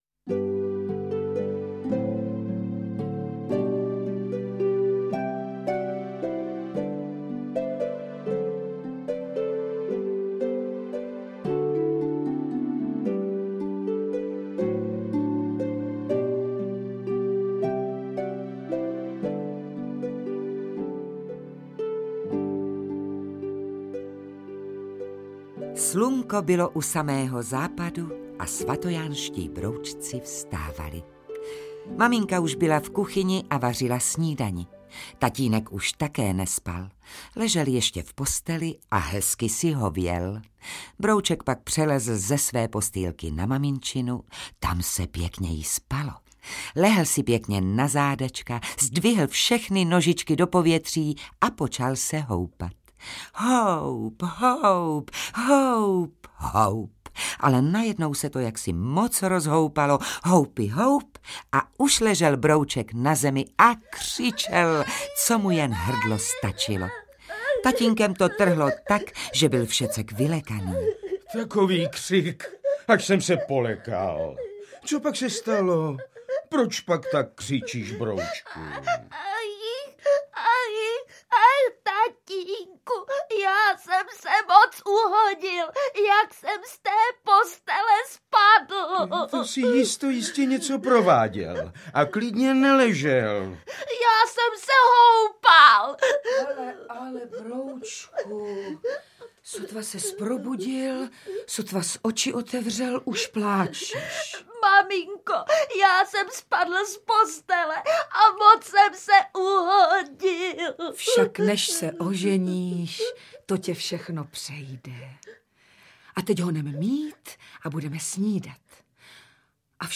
Interpreti:  Eliška Balzerová, Jana Drbohlavová
Pohádková dramatizace stejnojmenné knihy určená nejmladším posluchačům. Účinkují Věra Galatíková, Jitka Molavcová, Petr Štěpánek, Eliška Balzerová a další.